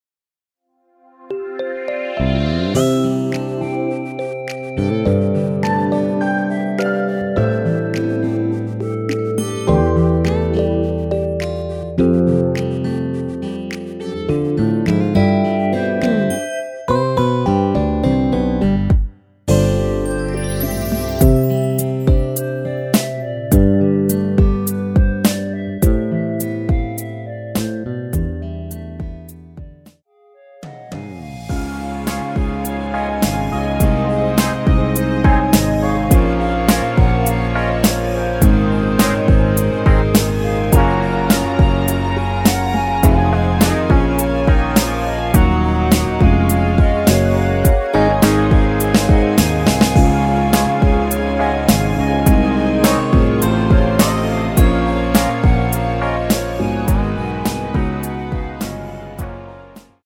◈ 곡명 옆 (-1)은 반음 내림, (+1)은 반음 올림 입니다.
음정과 박자 맞추기가 쉬워서 노래방 처럼 노래 부분에 가이드 멜로디가 포함된걸
앞부분30초, 뒷부분30초씩 편집해서 올려 드리고 있습니다.
중간에 음이 끈어지고 다시 나오는 이유는